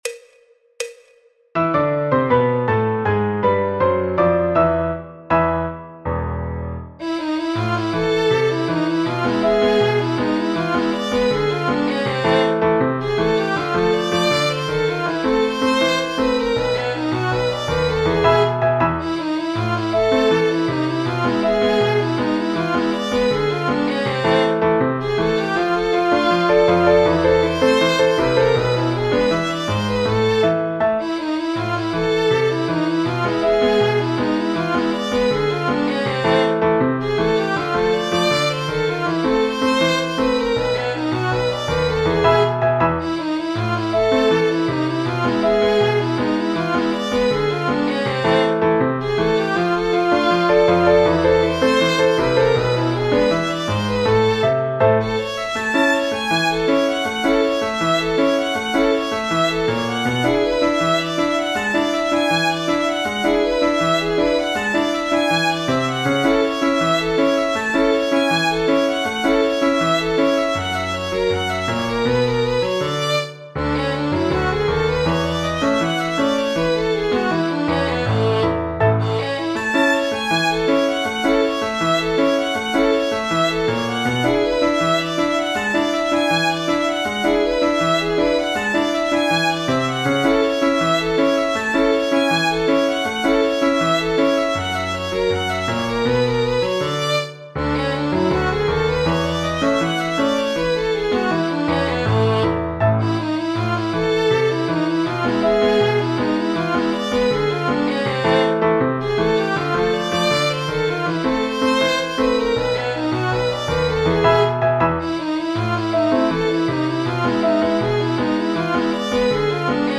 Choro, Jazz, Popular/Tradicional